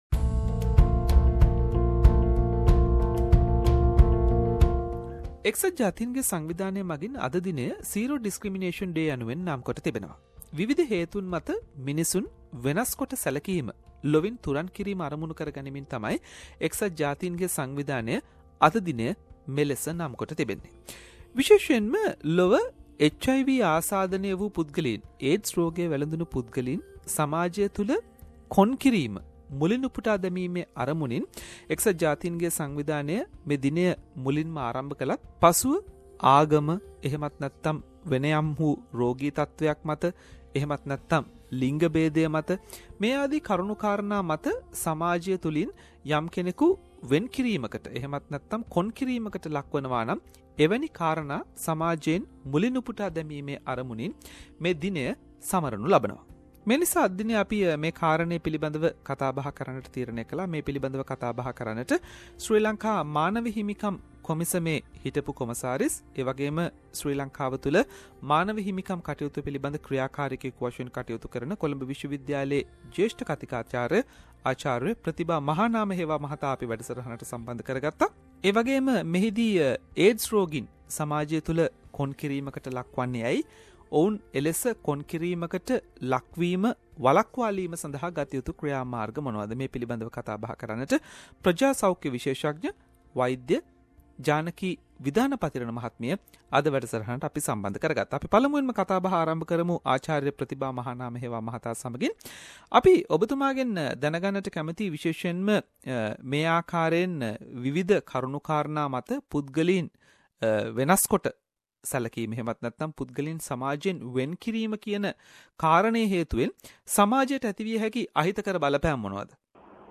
joined with us for the discussion.